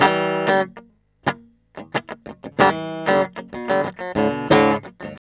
TELEDUAL G#2.wav